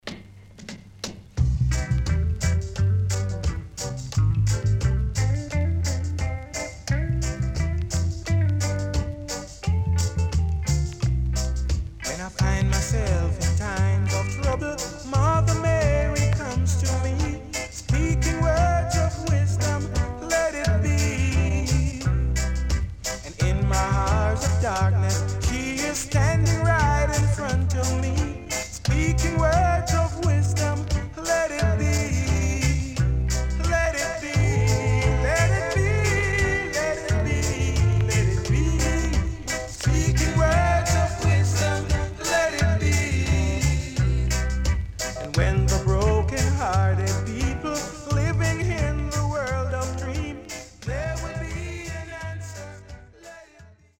哀愁漂うEarly Reggaeの名曲の数々を収録した名盤
SIDE A:全体的にチリプチノイズ入ります。